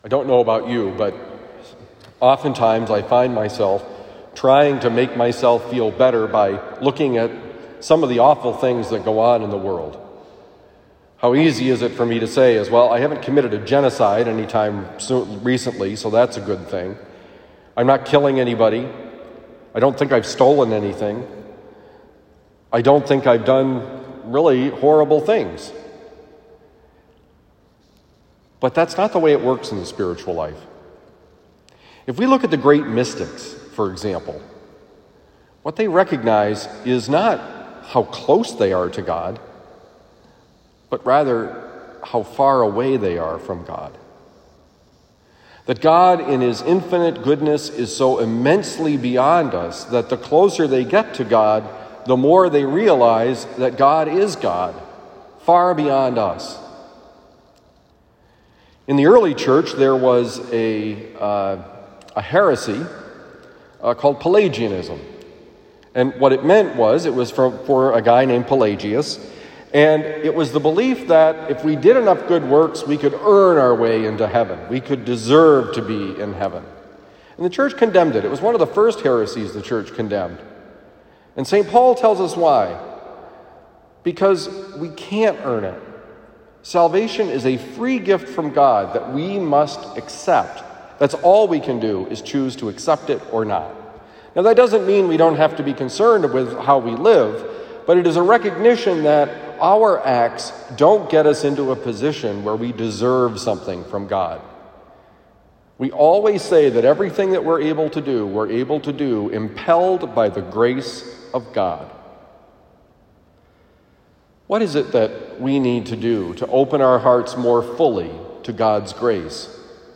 Homily for Thursday, October 14, 2021
Given at Christian Brothers College High School, Town and Country, Missouri.